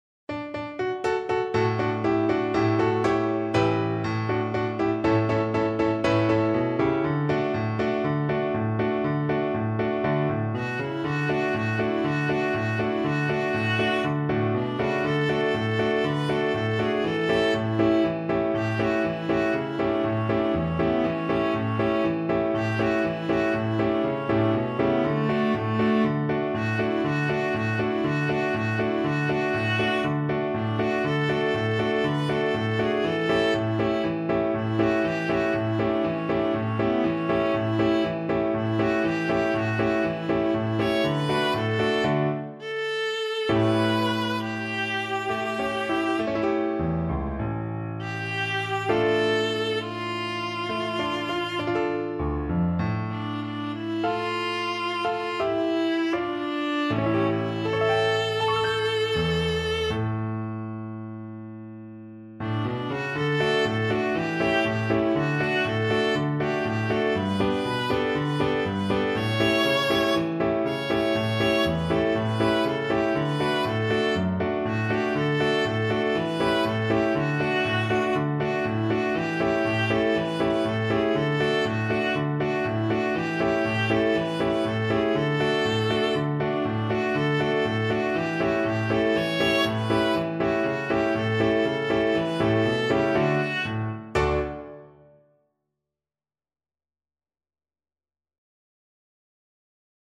Viola
2/4 (View more 2/4 Music)
Mariachi style =c.120
D major (Sounding Pitch) (View more D major Music for Viola )
Mexican